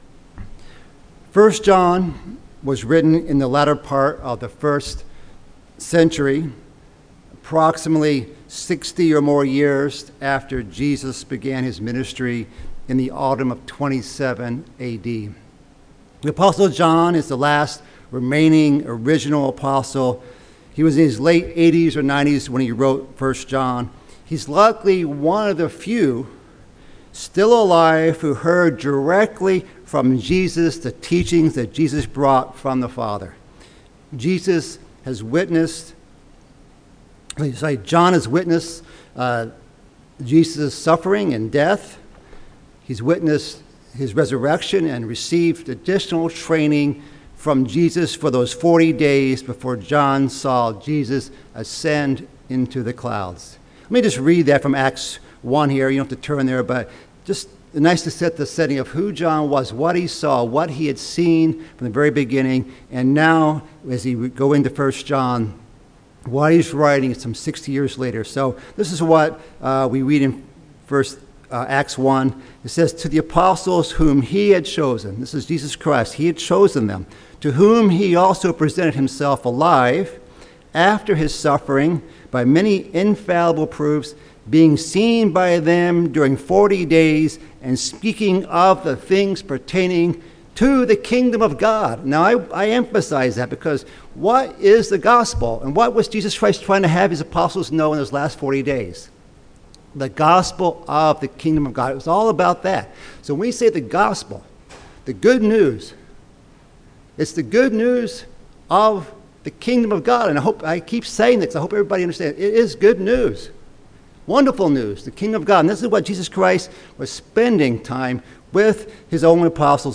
Bible Study: 1 John